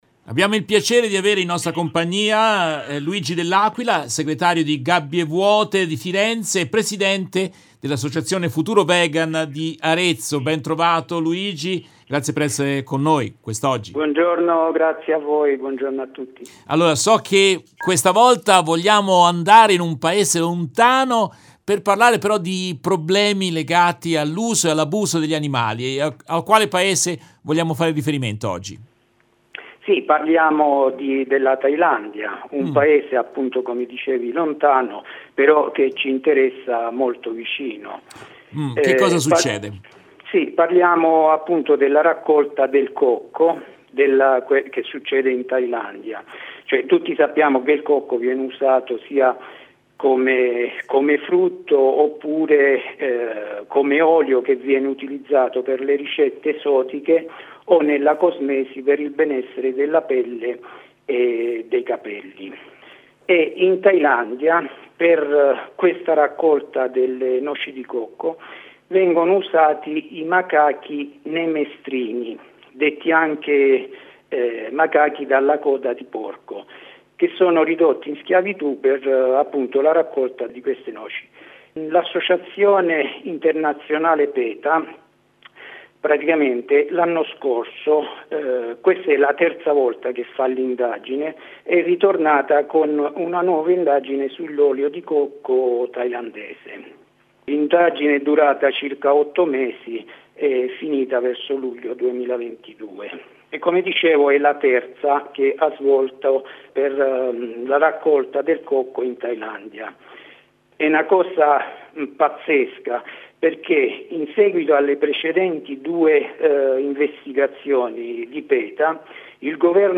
In questa puntata tratta dalla diretta RVS del 27 gennaio 2023